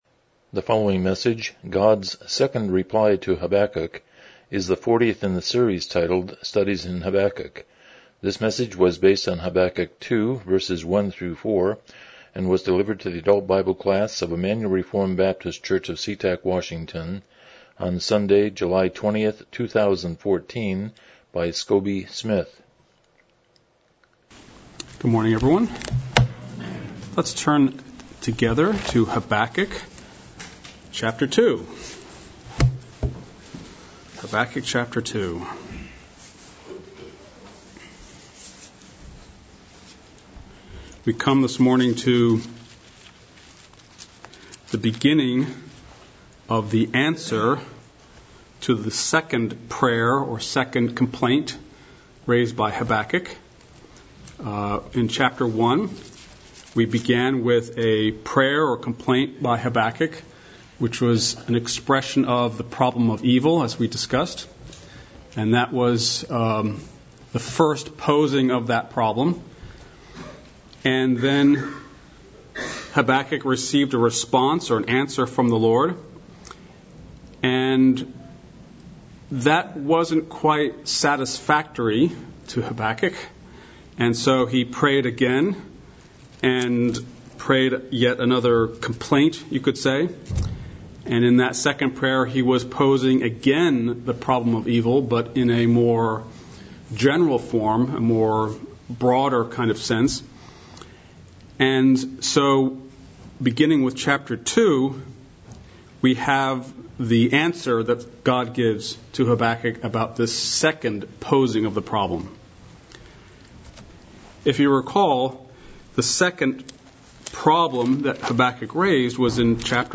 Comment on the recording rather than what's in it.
Studies in Habakkuk Passage: Habakkuk 2:1-4 Service Type: Sunday School « 58 The Parable of the Sower